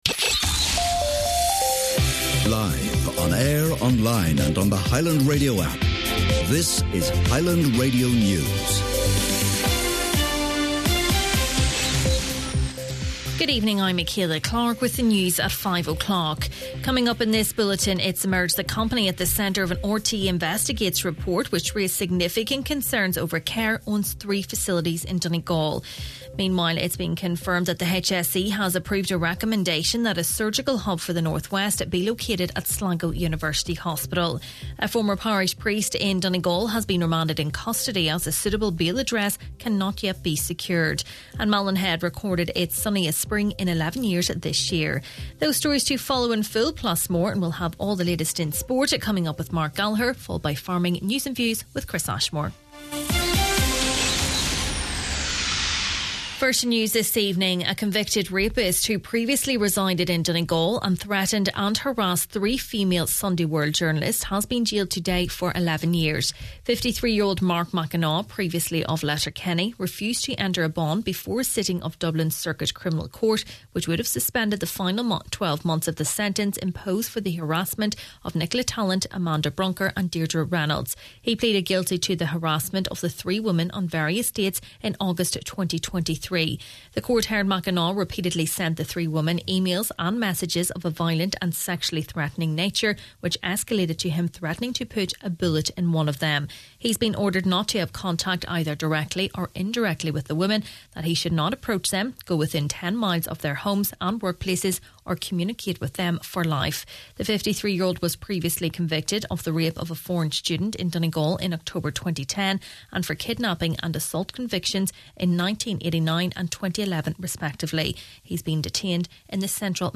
Main Evening News, Sport, Farming News and Obituaries – Thursday, June 5th